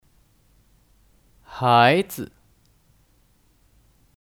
孩子 (Háizi 孩子)